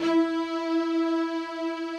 strings_052.wav